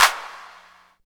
59 VERB CLAP.wav